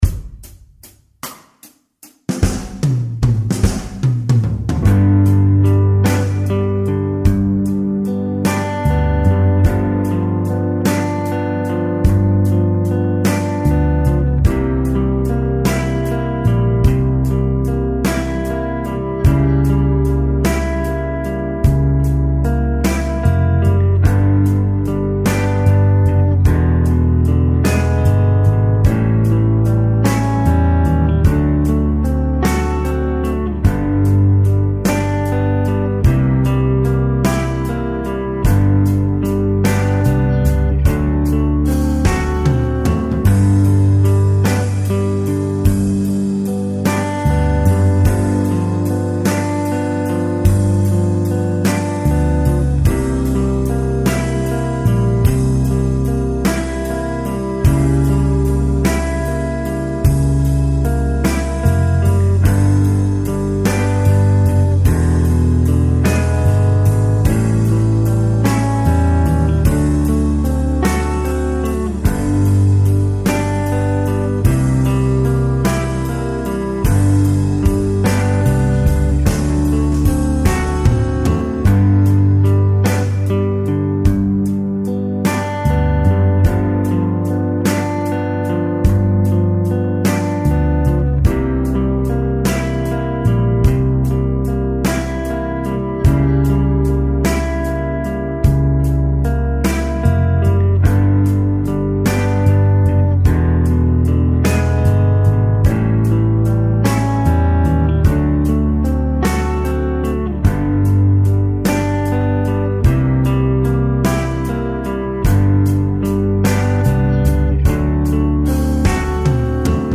la versione della base di durata ridotta e senza impro iniziale.
E' già pronta per essere messa in loop ed eseguita all'infinito.
Jam_Slow_Blues_Backing_Track.mp3